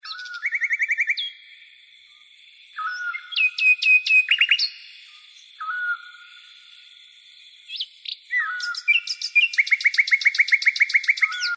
переливы птиц
Красивые и натуральные переливы птиц